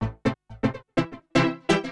SYNTH047_PROGR_125_A_SC3(R).wav
1 channel